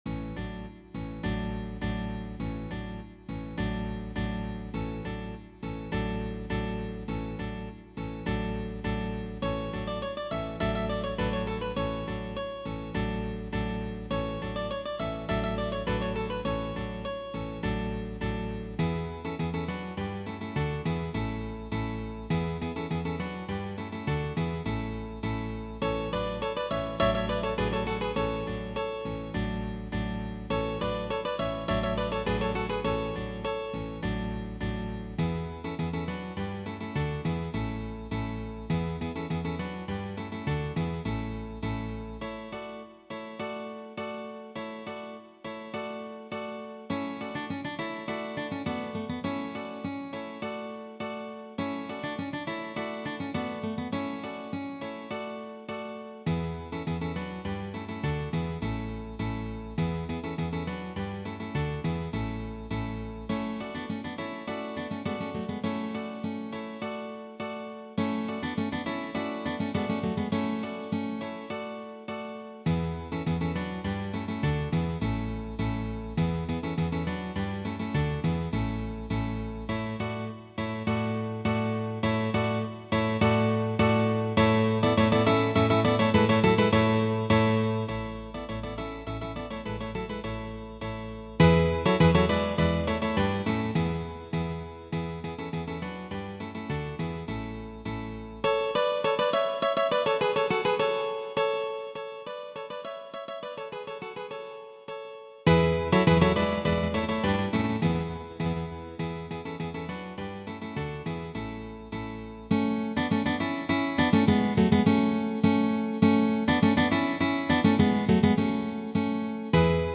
Besetzung: Zupforchester
Wirkungsvolles, leicht spielbares Stück für Zupforchester